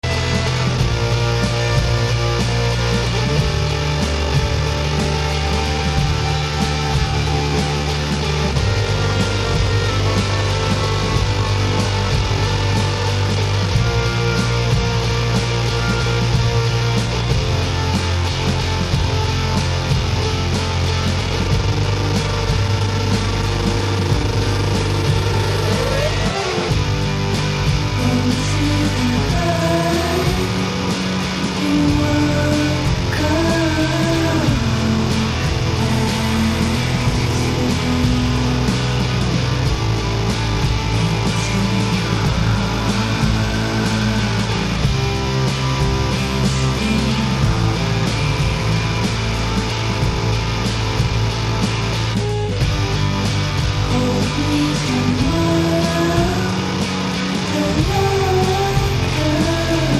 com um som novo, exigente e cheio de vontade de experimentar